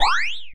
sci-fi_weapon_laser_small_fun_03.wav